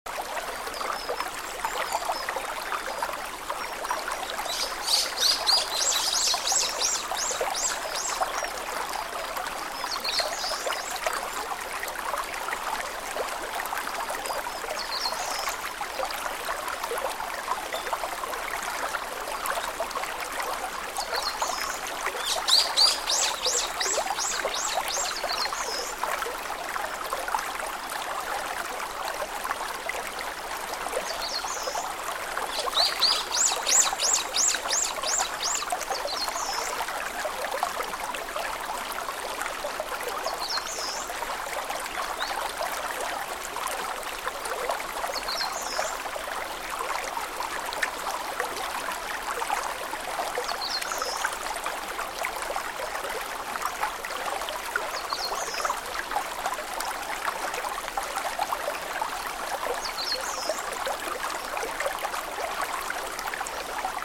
Healing Sounds from a Forest Valley
Clear stream sounds, fresh breeze, and the gentle calls of wild birds—